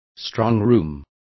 Complete with pronunciation of the translation of strongrooms.